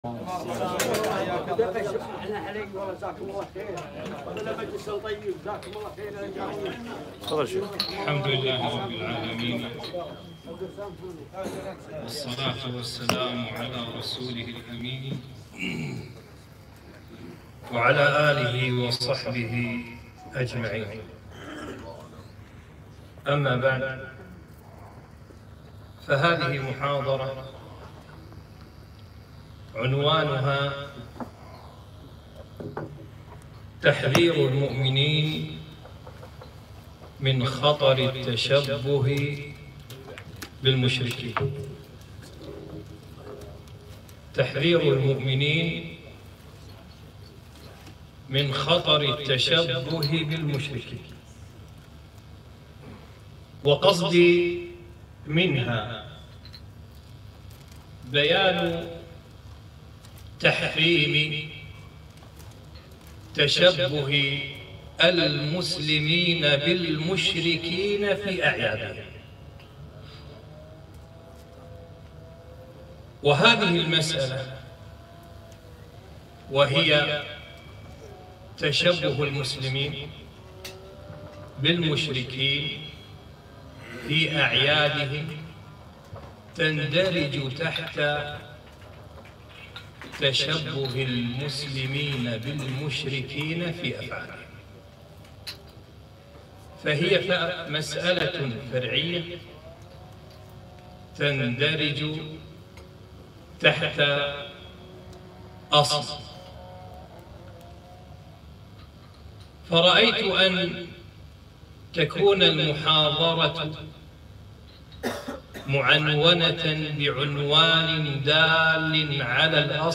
محاضرة - تحذير المؤمنين من خطر التشبه بالمشركين